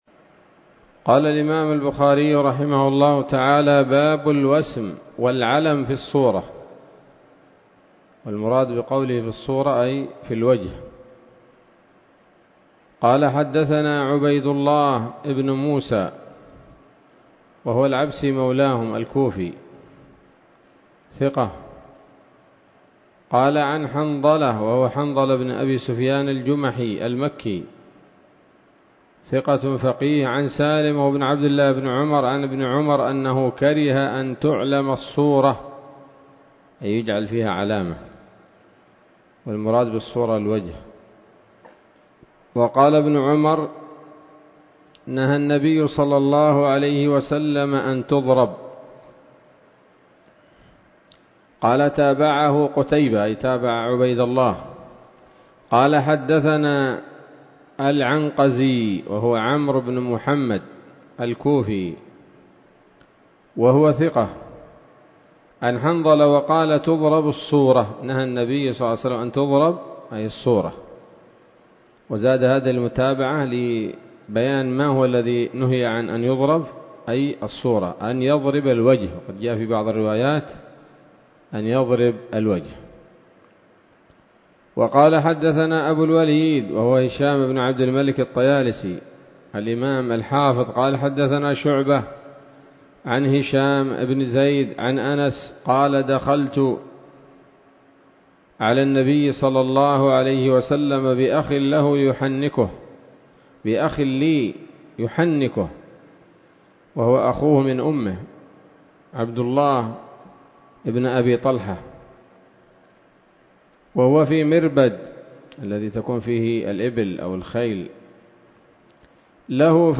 الدرس الثلاثون من كتاب الذبائح والصيد من صحيح الإمام البخاري